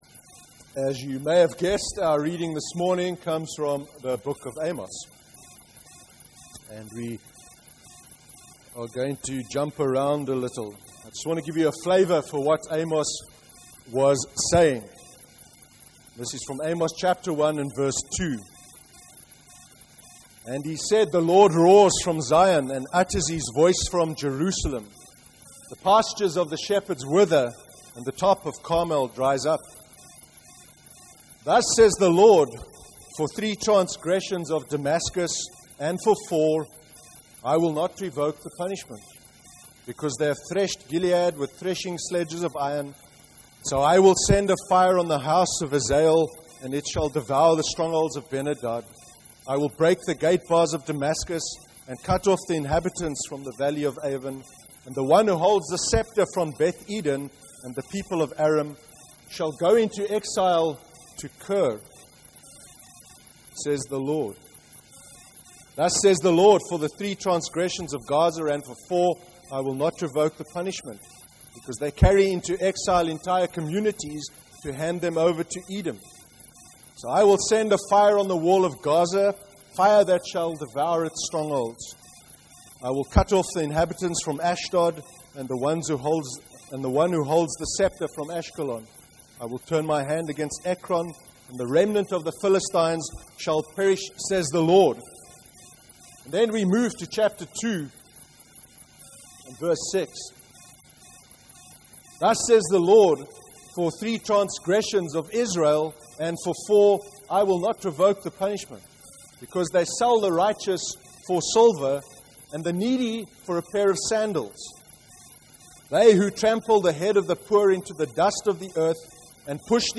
19/10/2014 sermon: Amos (Amos 1:2-8 and Amos 2:6-16)